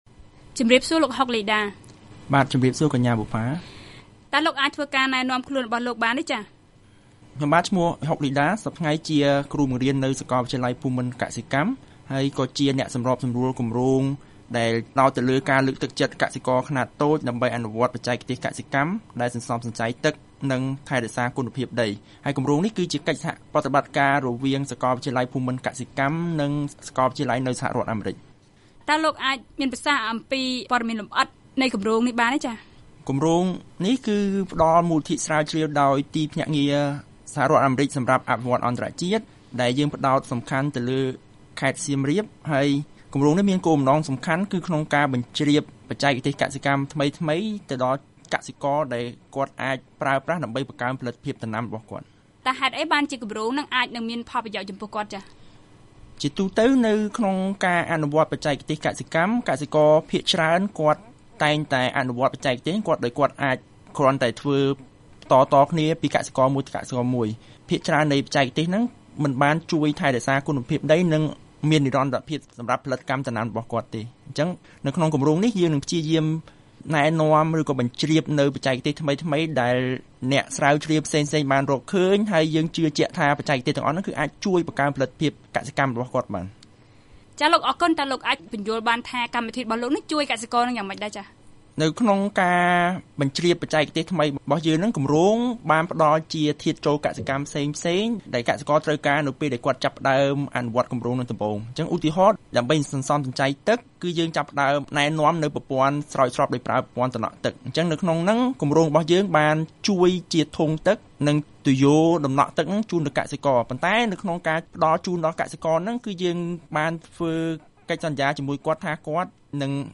បទសម្ភាសន៍ VOA៖ បច្ចេកទេសកសិកម្មសន្សំសំចៃទឹក រក្សាគុណភាពដី